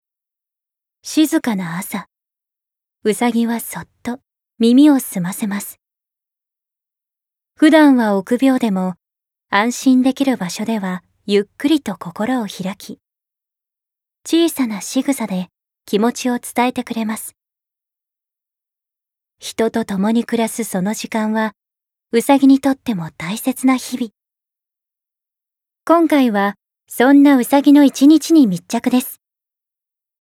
ボイスサンプル
ナレーション４